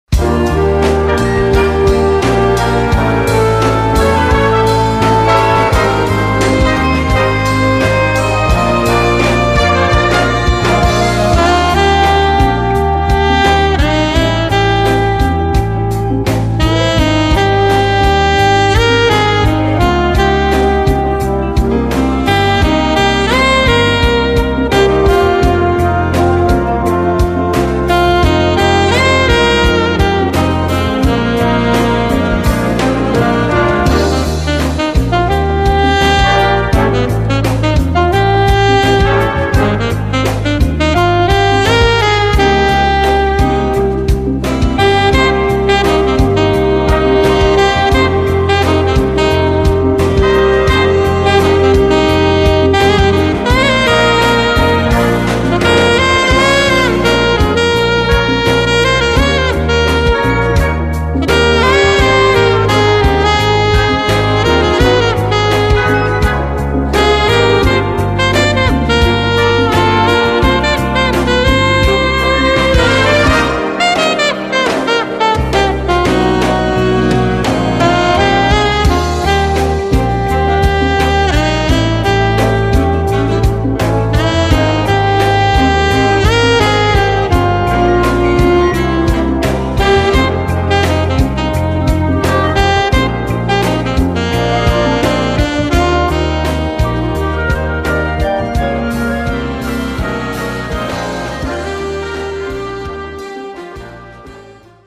Voicing: Euphonium and Brass Band